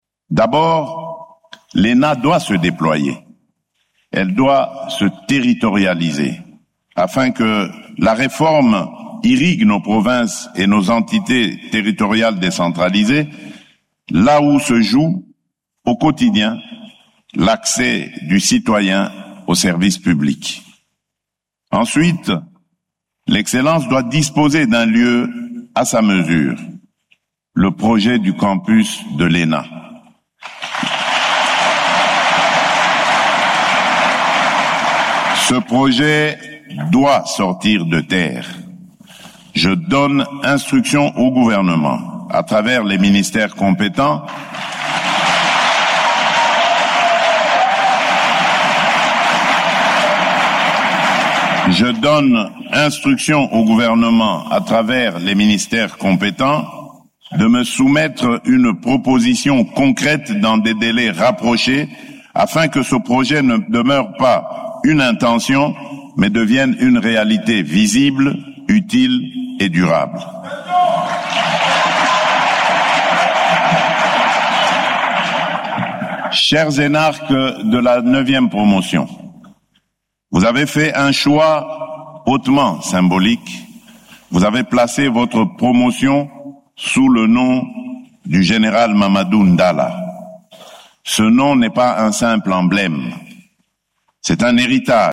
Félix Tshisekedi a formulé cette demande mardi 10 février, lors de la cérémonie couplée marquant l’entrée de la Xe promotion et la sortie de la IXe promotion des élèves de l’ENA, à Kinshasa.
Ecoutez un extrait du discours de Félix Tshisekedi :